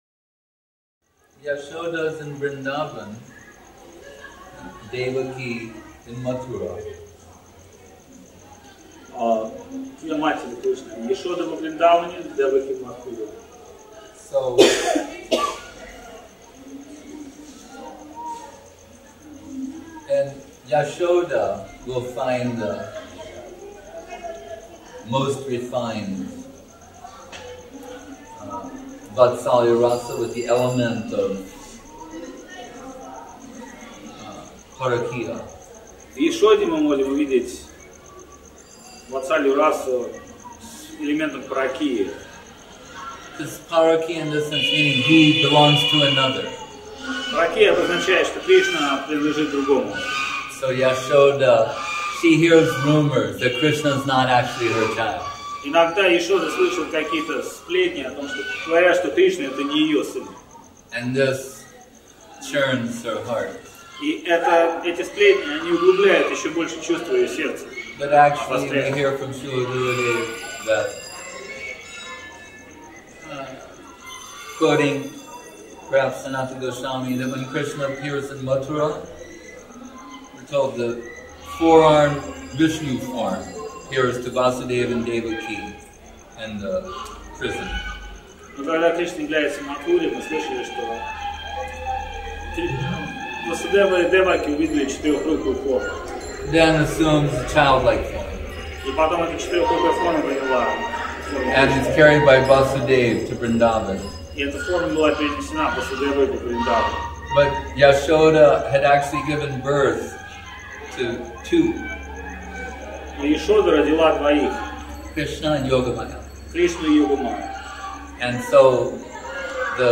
Place: SCSMath Nabadwip